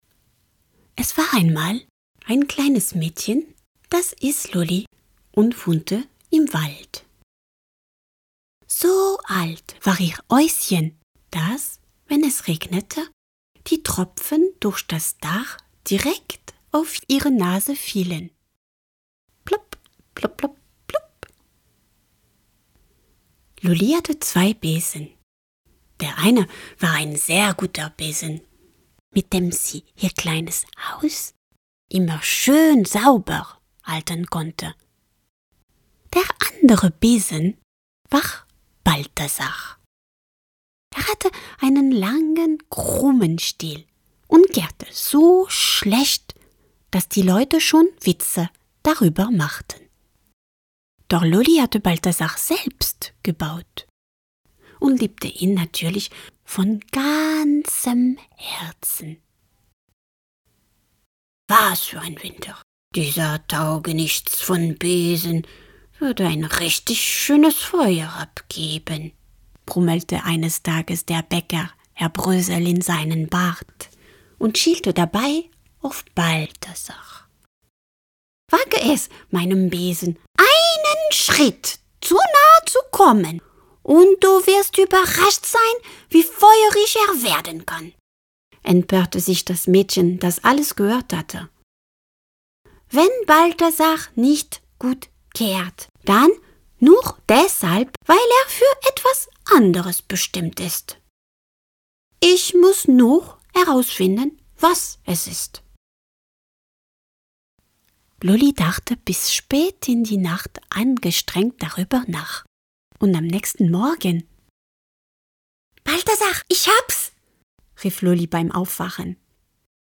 HÖRPROBE von Loli will das Hexen lernen…